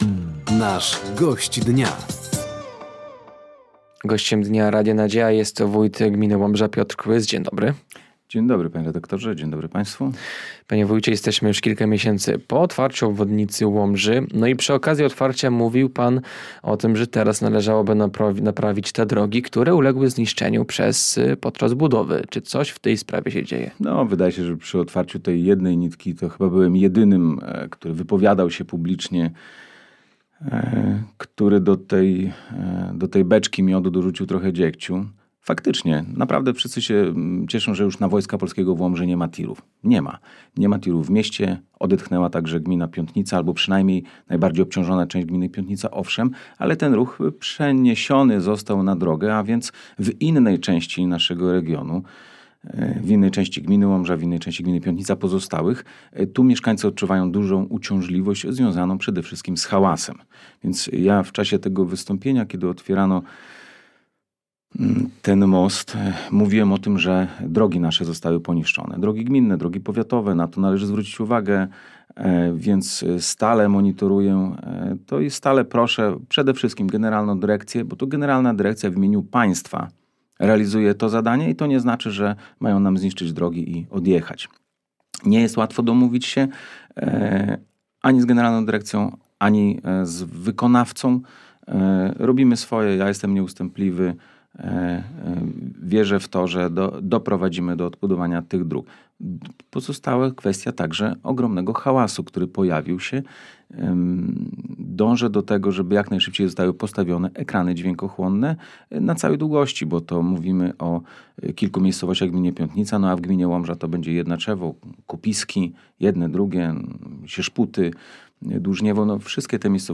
Wójt gminy Łomża Piotr Kłys był Gościem Dnia Radia Nadzieja. Tematem rozmowy była między innymi naprawa dróg zniszczonych w wyniku budowy obwodnicy Łomży, współpraca z miastem Łomża w kontekście między innymi darmowych przejazdów komunikacją miejską dla uczniów oraz uszczelnienie systemu śmieciowego.